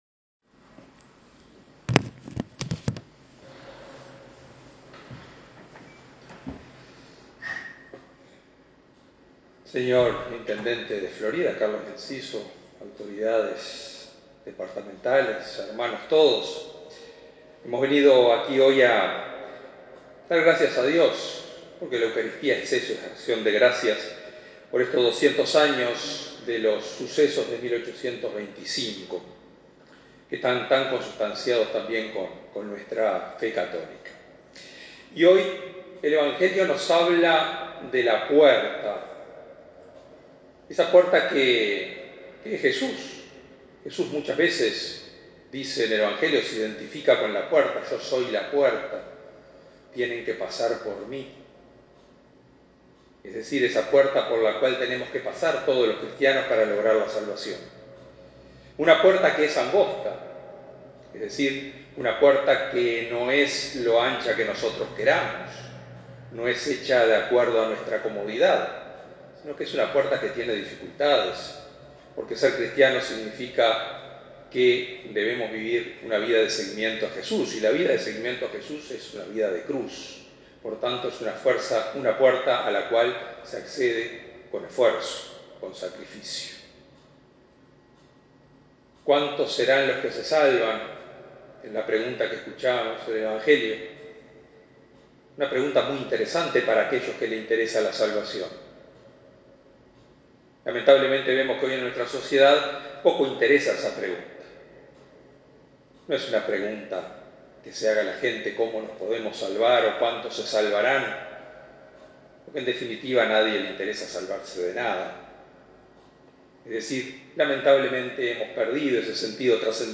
Homilía_Audio Antes de la bendición final
Misa por la patria_Homilía.mp3